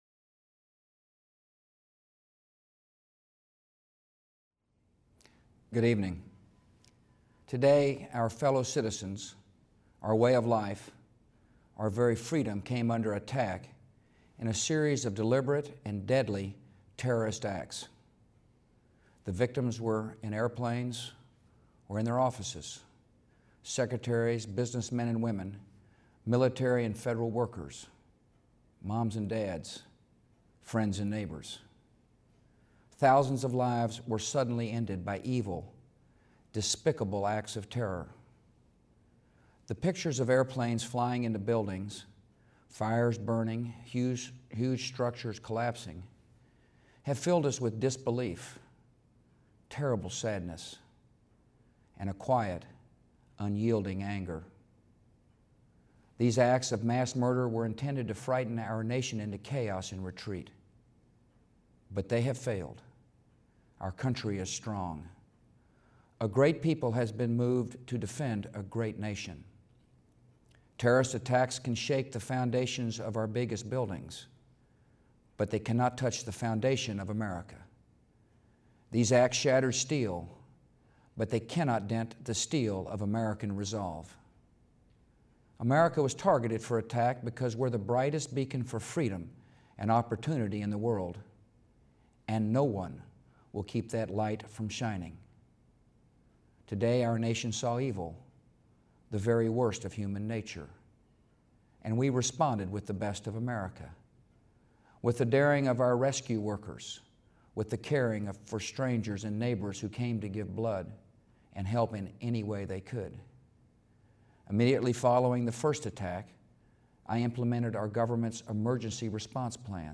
Presidential Speeches
President Bush addresses the nation on the terrorist attacks which occurred on the morning of September 11, 2001.